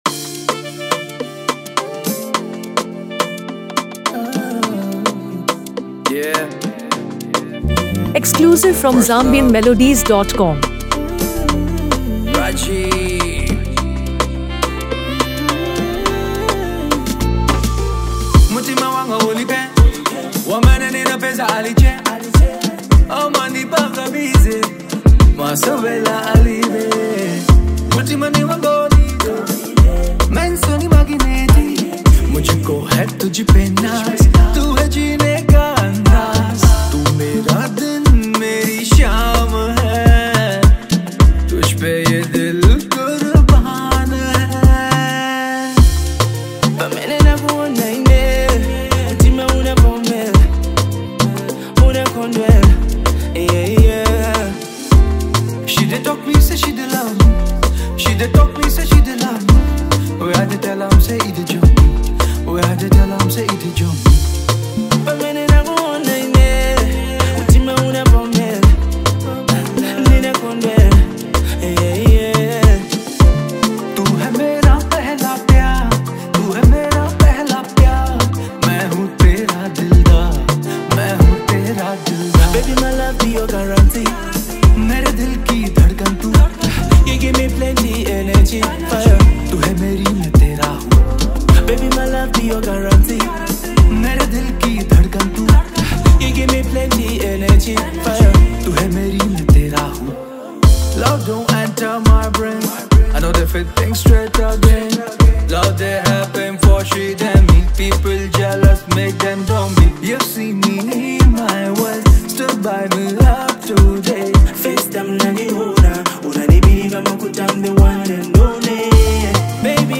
Afro-soul and R&B